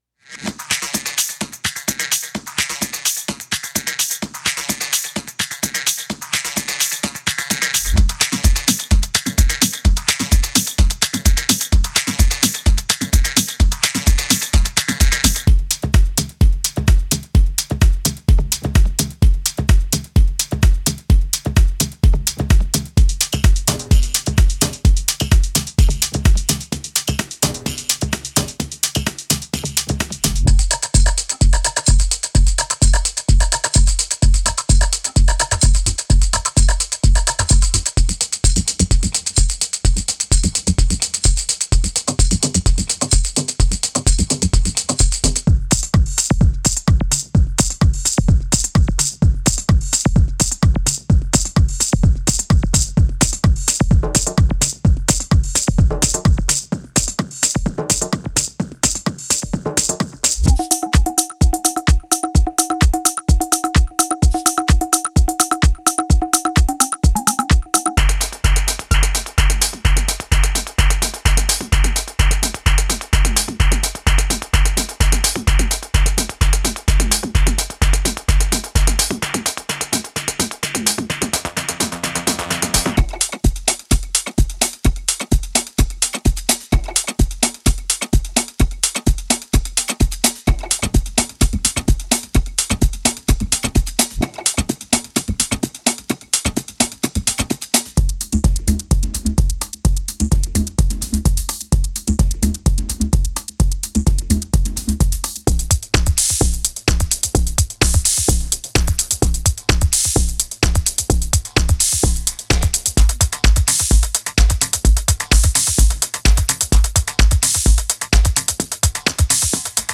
Genre:Techno
パート2では、エレクトロンAnalog Rytm、エレクトロンModel Cycles、Jomox Alpha Baseを使用してシーケンスされた、128 BPMで録音された100の精巧にプログラムされたドラムヒットと100のループを収録しています。
複雑なパターンがトラックに催眠的なエネルギーをもたらします。
これらのループはManley Massive Passive EQを通して処理され、シルキーでスムーズな高域を実現しています。
ダークで荒々しく、エッジの効いたパーカッシブなリフが、テクスチャー豊かで鮮やかなサウンドパレットを提供します。
シンプルにまとめられたハイハット、クラップ、ノイズループをレイヤーし、より洗練されたリズムを作成します。
適度にコンプレッションとEQ処理が施されており、すぐにミックスに馴染むように調整されています。